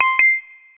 talk_start.wav